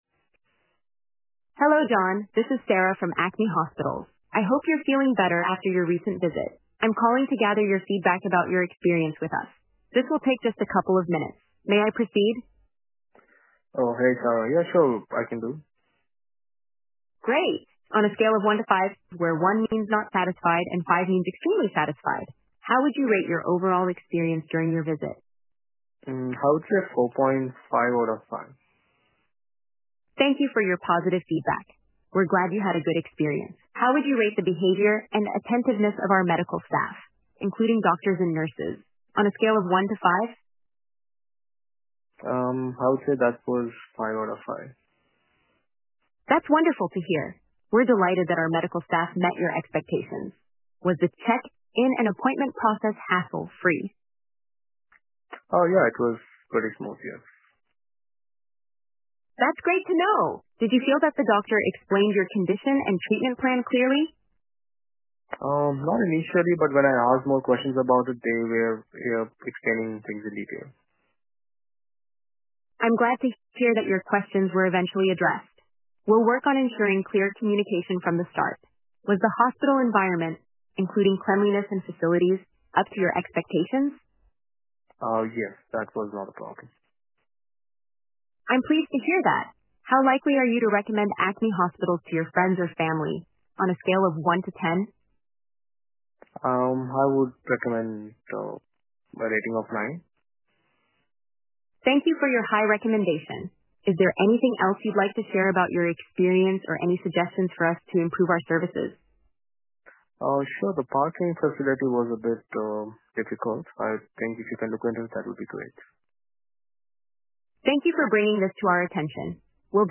Reimagine patient engagement with Callmatic’s AI Voice Bot.